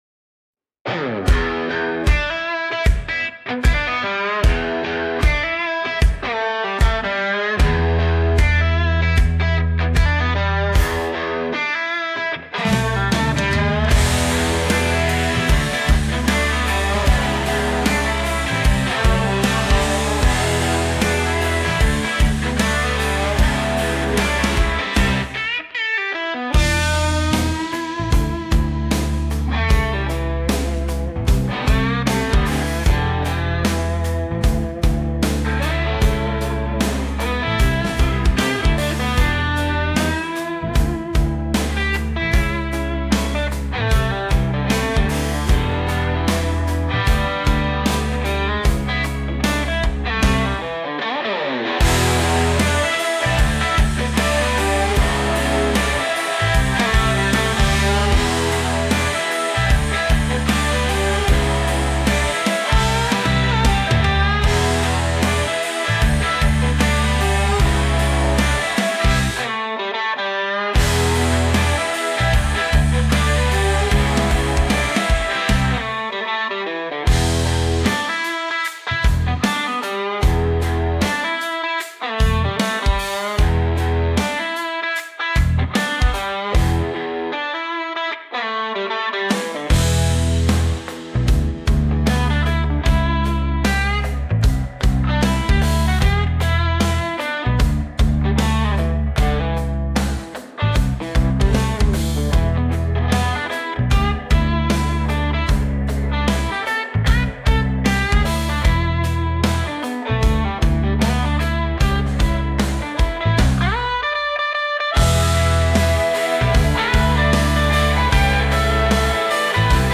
Country Stock Audio Tracks